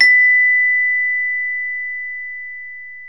CELESTE B4.wav